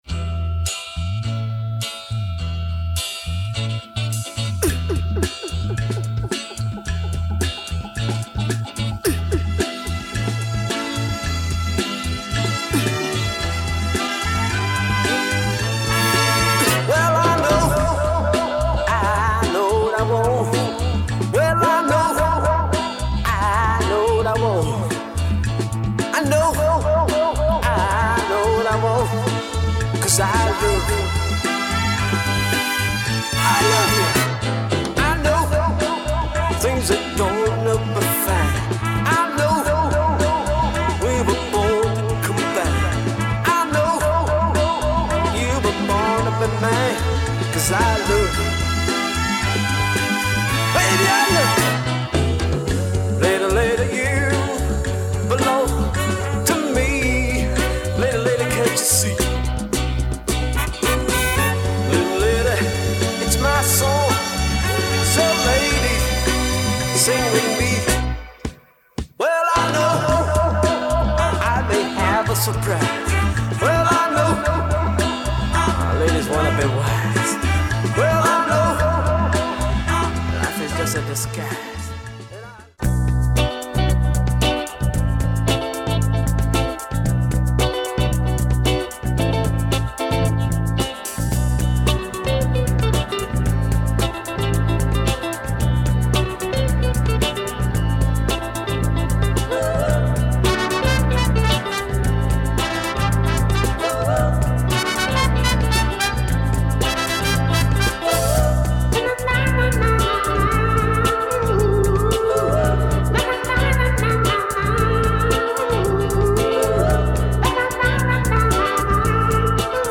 Brazilian pop singer